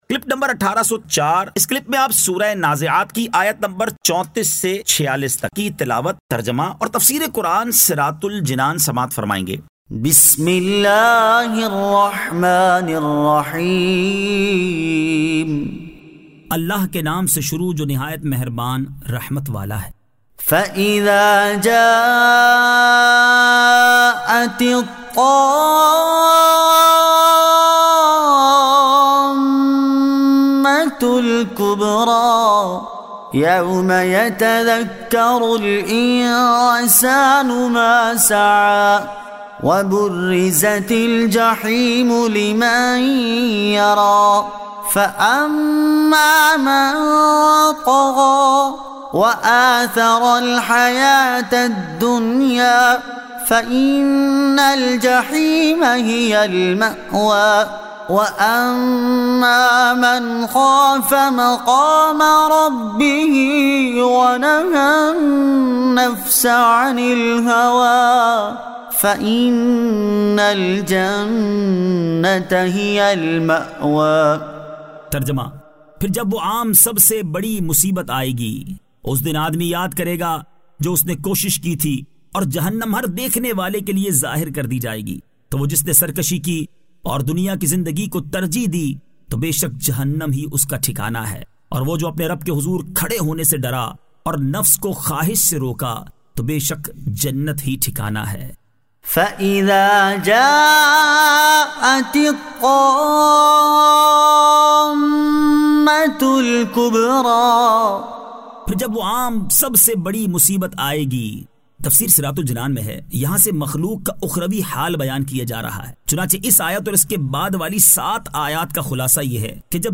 Surah An-Nazi'at 34 To 46 Tilawat , Tarjama , Tafseer
2024 MP3 MP4 MP4 Share سُوَّرۃُ النَّازِعَاتِ آیت 34 تا 46 تلاوت ، ترجمہ ، تفسیر ۔